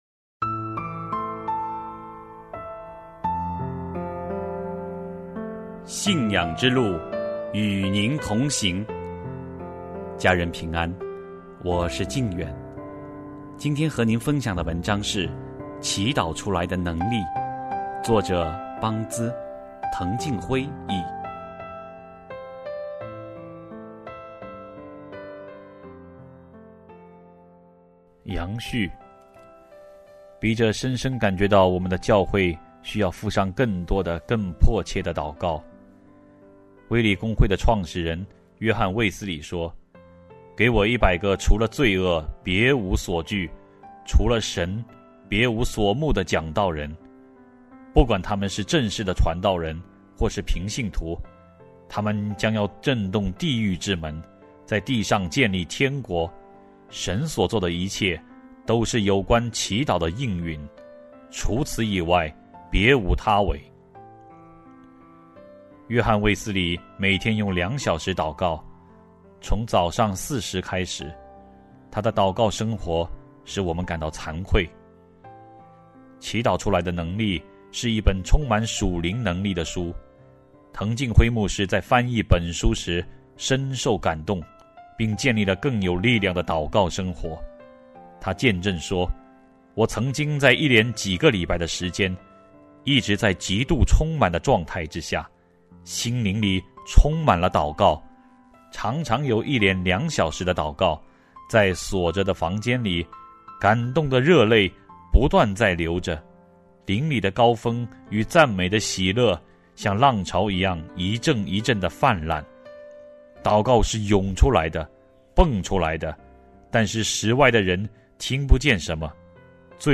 首页 > 有声书 | 灵性生活 | 祈祷出来的能力 > 祈祷出来的能力：杨、白●合序